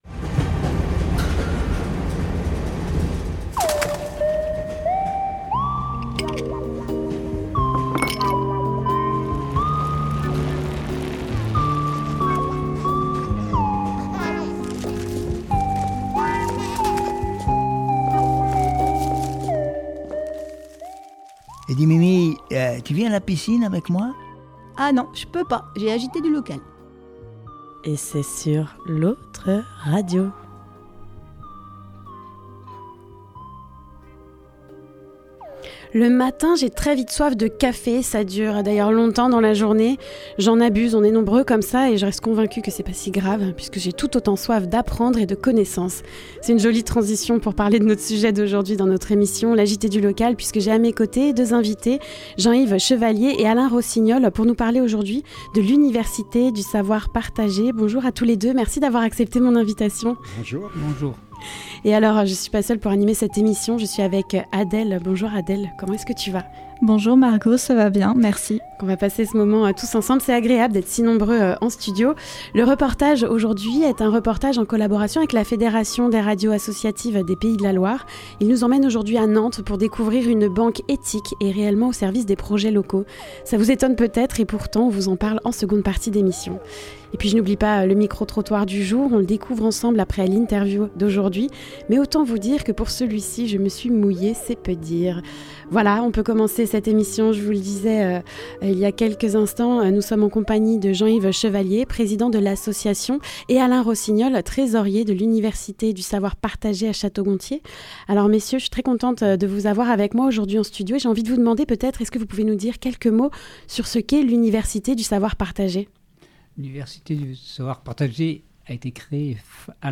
Le reportage Penser Local : La NEF, banque éthique au service de projets locaux